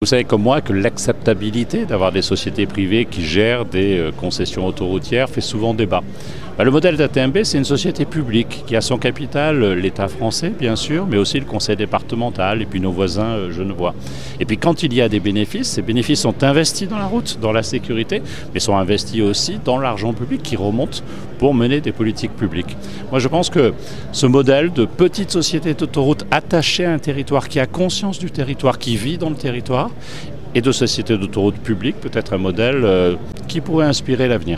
C'était la semaine dernière à l'occasion de la cérémonie des vœux de la société d'Autoroute.
La prise de parole du nouveau président d’ATMB, Christophe Castaner, a été l’occasion d’un tour d’horizon des valeurs de la société.